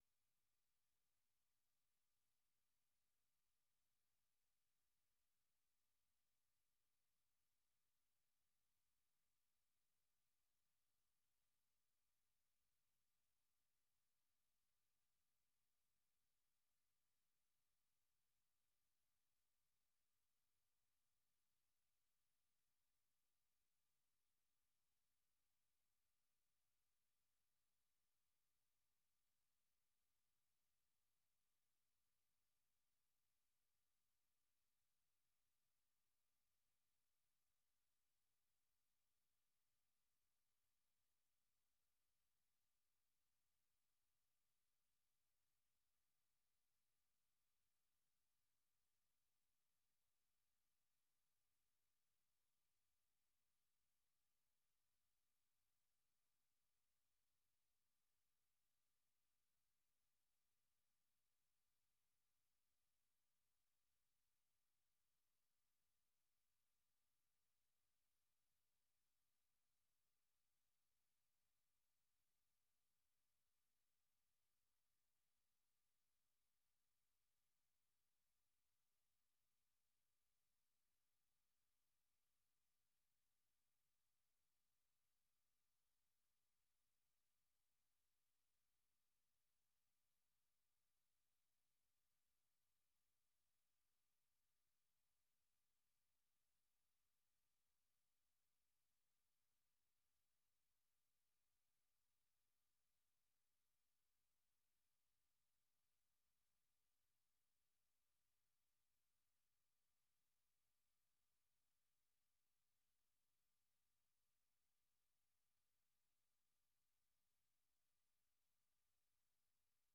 1 Learning English Podcast - September 26, 2025 29:56 Play Pause 14h ago 29:56 Play Pause Play later Play later Lists Like Liked 29:56 Learning English use a limited vocabulary and are read at a slower pace than VOA's other English broadcasts.